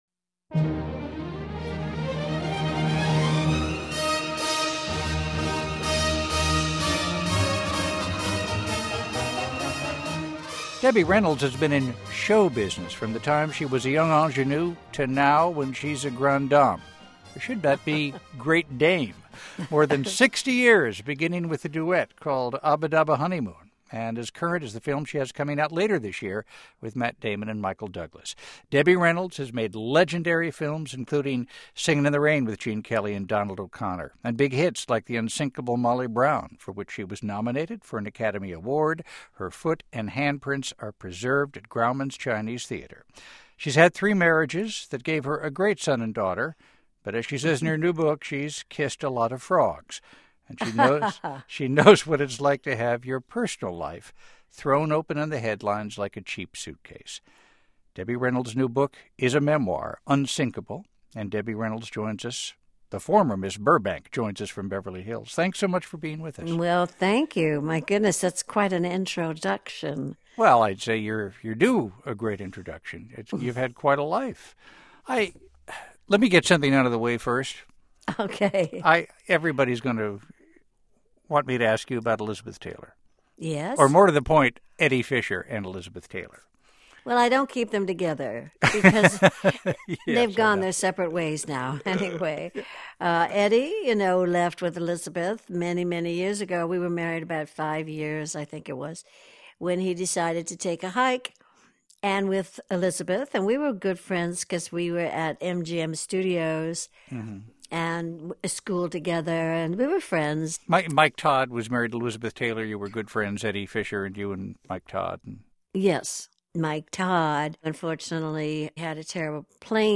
Debbie Reynolds – Interview with Scott Simon – Weekend All Things Considered – March 30, 2013 – NPR –
The occasion is the publication of her memoirs, Unsinkable. Her interview is down-to-earth and honest, unflinching.
Debbie-Reynolds-Scott-Simon-interview.mp3